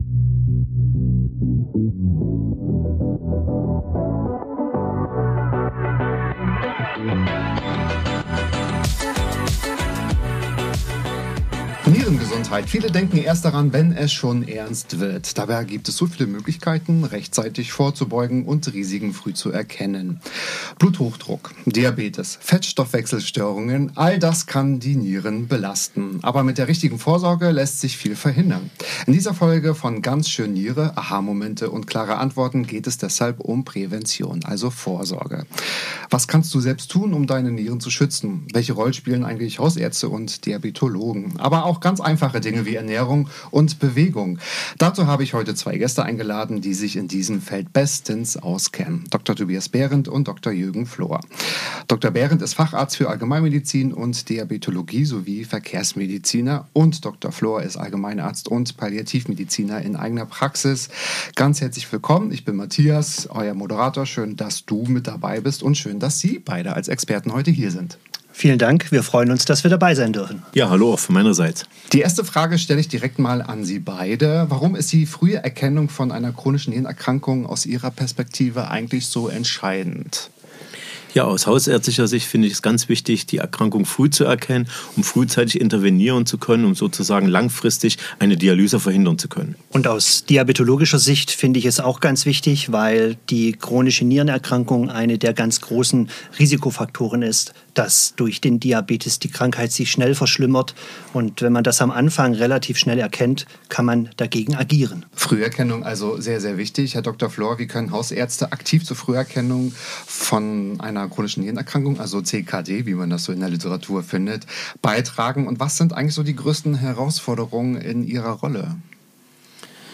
Ein Gespräch voller Aha-Momente, das zeigt: Mit der richtigen Vorsorge kann jeder aktiv dazu beitragen, seine Nieren langfristig gesund zu halten.